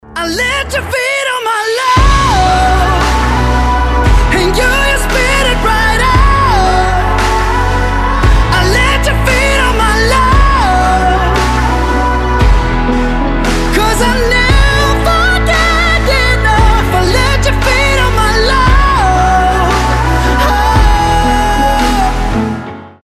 поп
мужской вокал
vocal